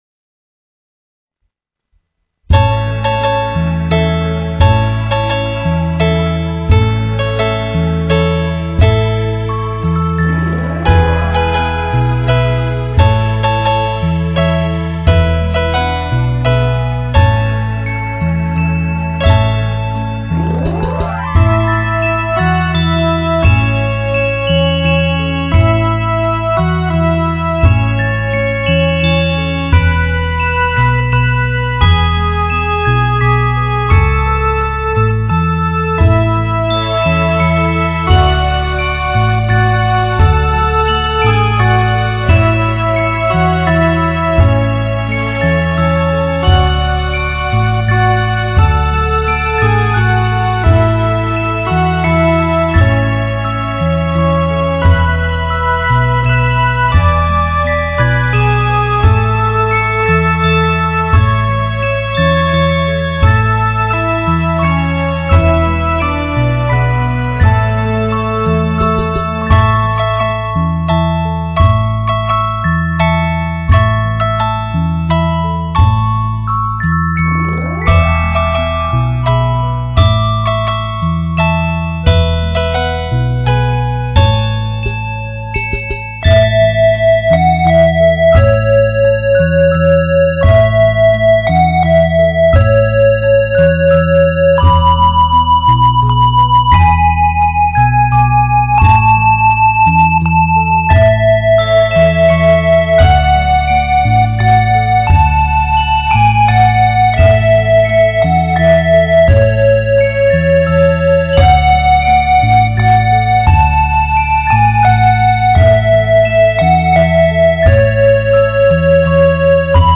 专辑类型：DTS-CD